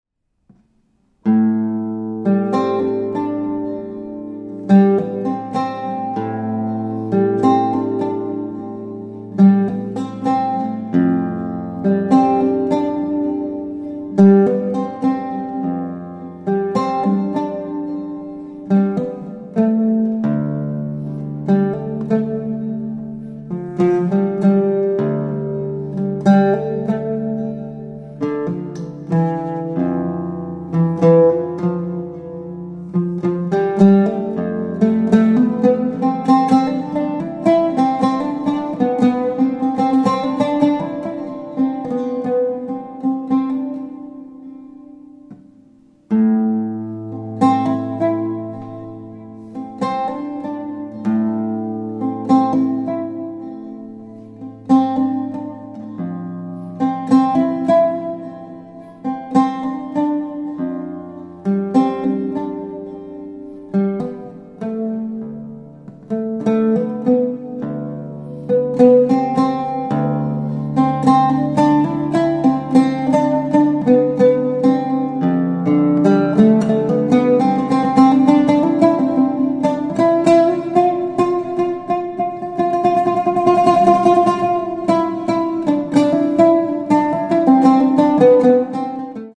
oud